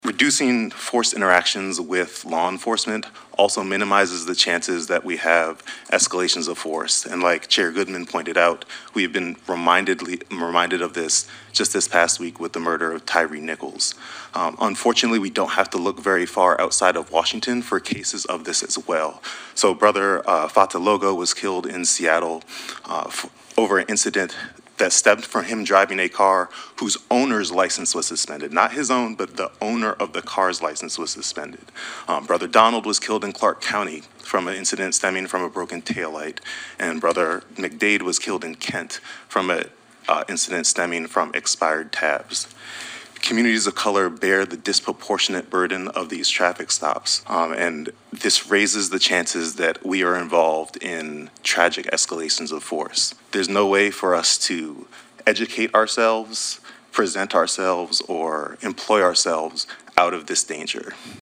Representative Chipalo Street (D-Seattle) sponsors the legislation. He says it would improve community safety while building trust between law enforcement and “communities of color.”